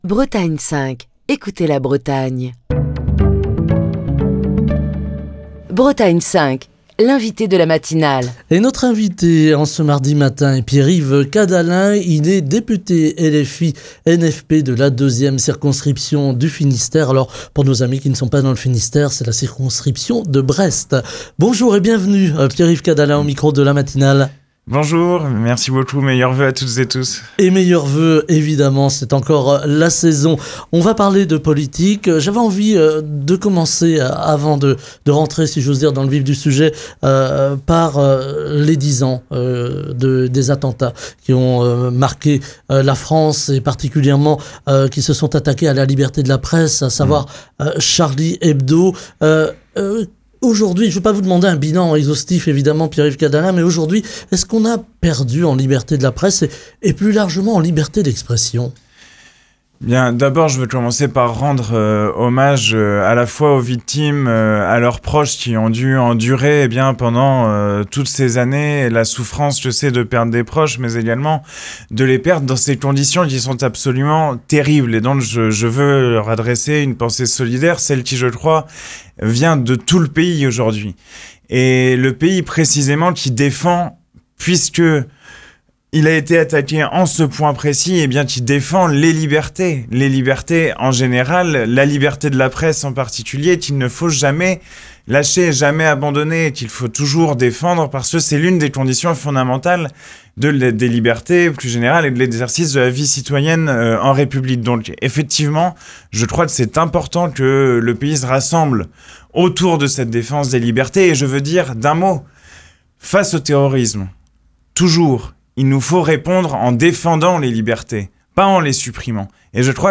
Pierre-Yves Cadalen, député LFI-NFP de la deuxième circonscription du Finistère, était l’invité politique de la matinale de Bretagne 5. Dix ans après les attentats islamistes survenus entre le 7 et le 9 janvier 2015, qui ont coûté la vie à 17 personnes, Pierre-Yves Cadalen a tenu à rendre hommage aux victimes.